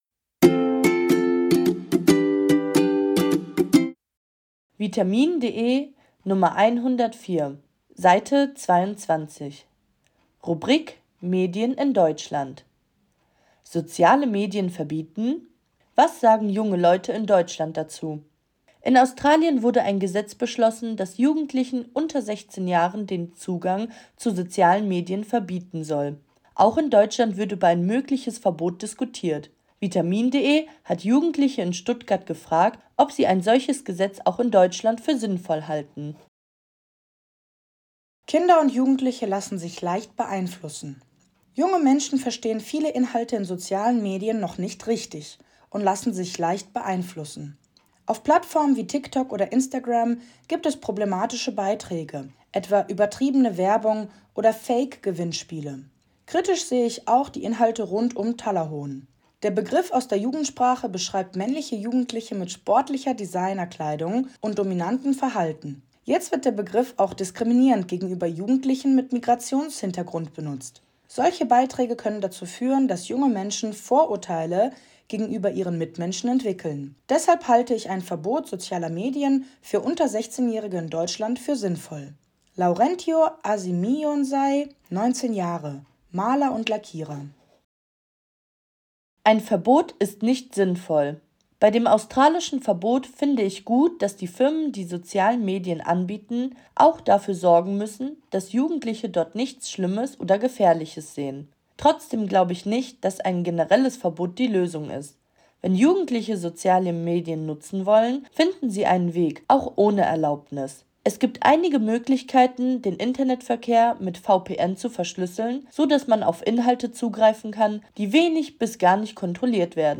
Audiodatei (Hörversion) zum Text
Sprecherin der Audios